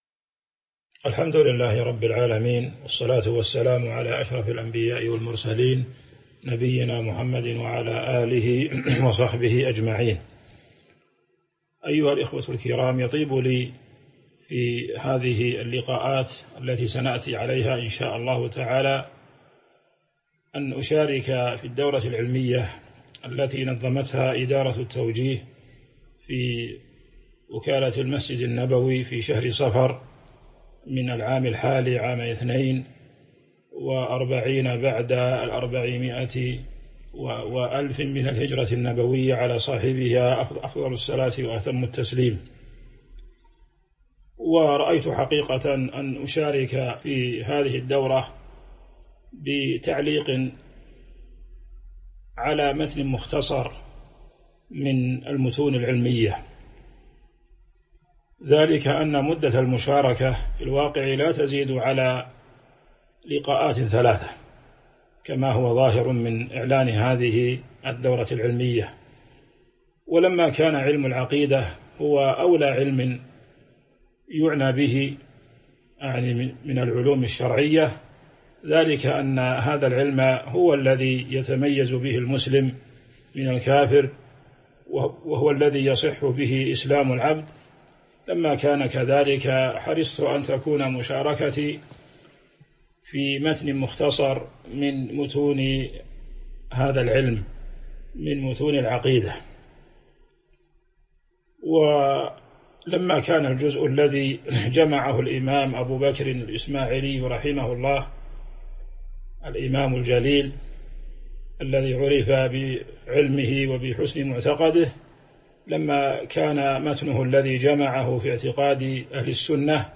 تاريخ النشر ٢١ صفر ١٤٤٢ هـ المكان: المسجد النبوي الشيخ